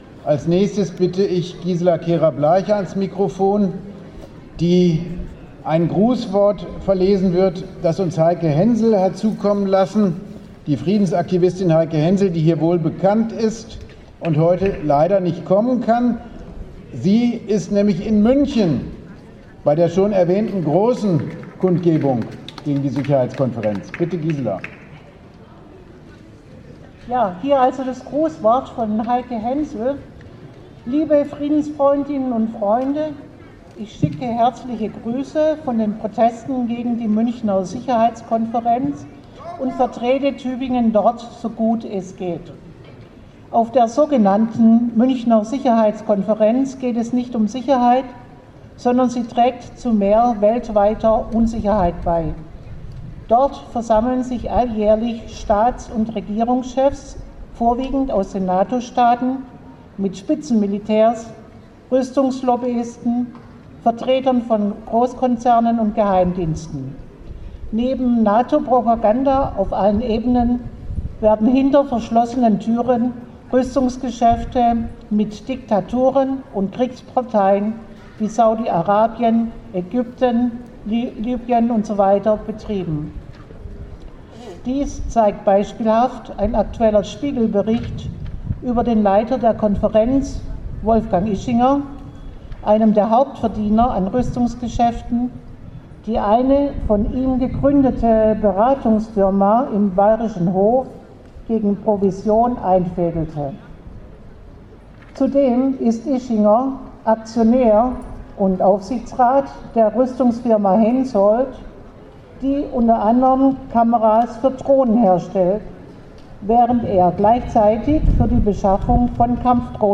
Anlässlich der sogenannten "Sicherheits"konferenz in München fanden in vielen Städten Proteste statt, so auch in Tübingen.
Auch ein Grußwort von Heike Hänsel wurde verlesen. Sie selbst war bei den Protesten in München und machte in dem Grußwort klar, dass es bei der sogenannten "Sicherheits"konferenz nie um Sicherheit ging.